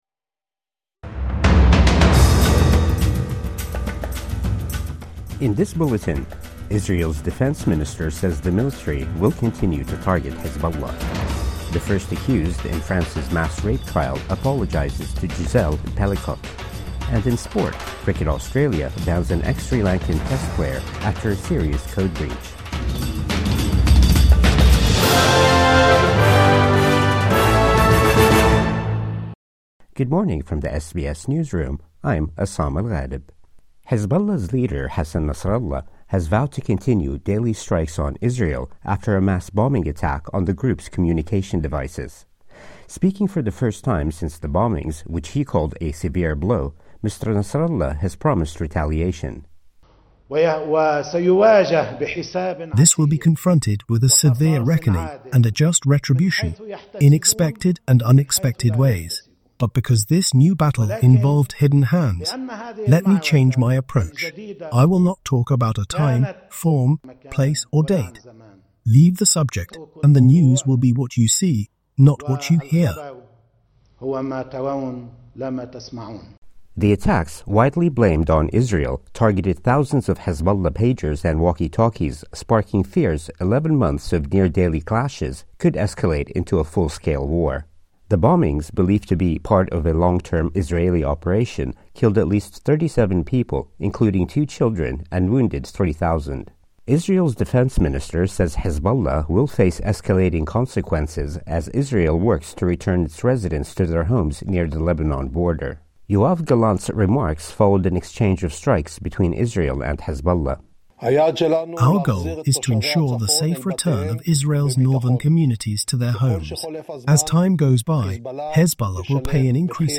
Morning News Bulletin 20 September 2024